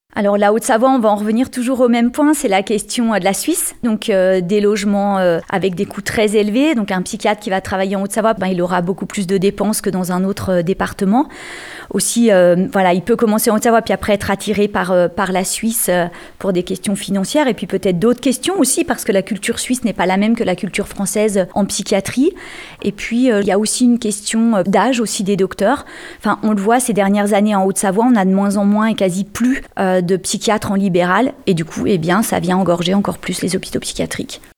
A cela s'ajoute, en Haute-Savoie, des problématiques particulières, notamment en termes de recrutement des praticiens, comme l'explique Christelle Petex.